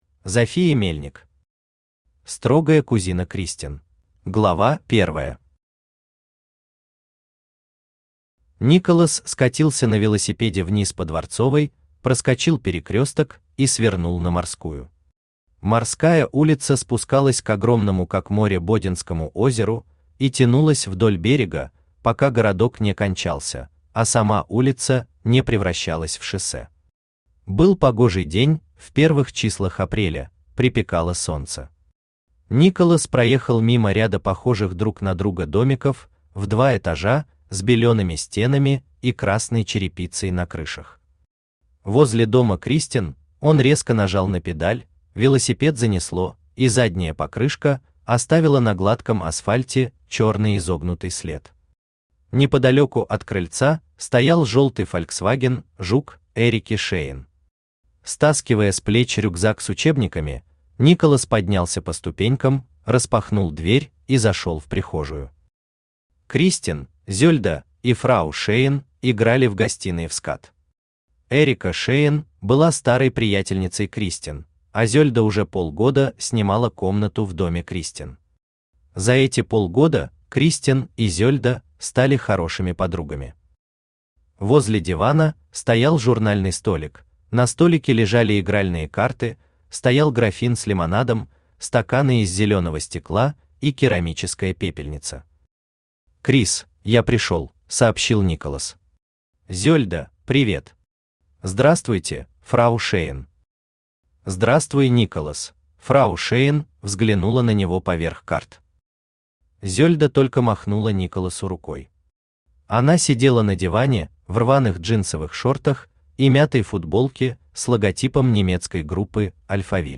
Аудиокнига Строгая кузина Кристен | Библиотека аудиокниг
Aудиокнига Строгая кузина Кристен Автор Зофия Мельник Читает аудиокнигу Авточтец ЛитРес.